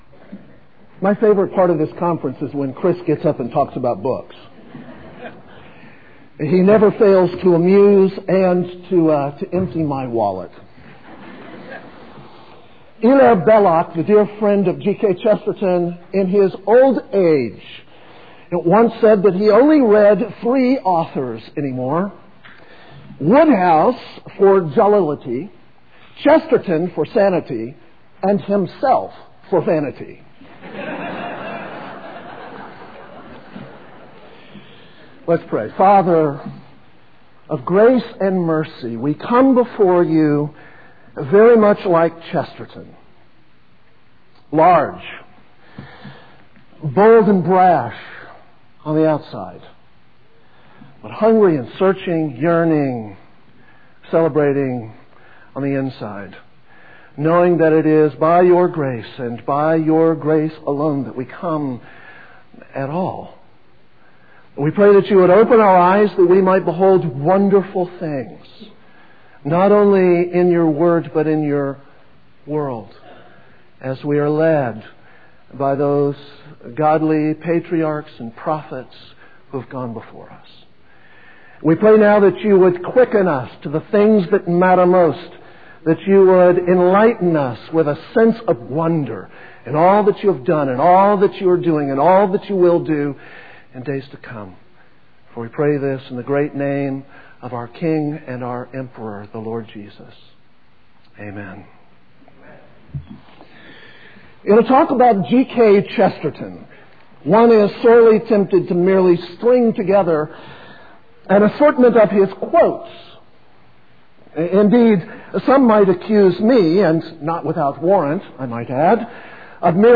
2002 Workshop Talk | 0:55:38 | All Grade Levels